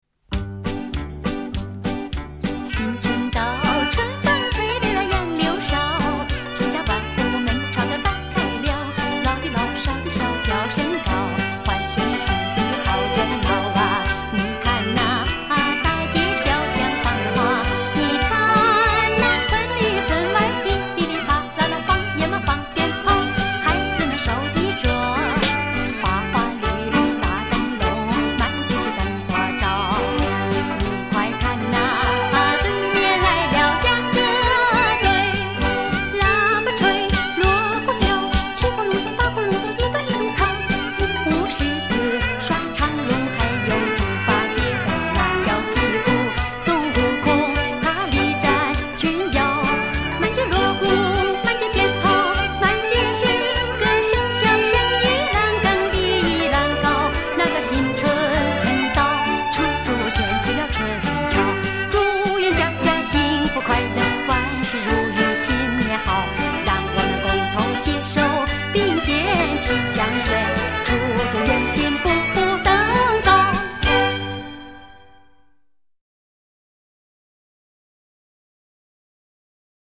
音乐类型：民歌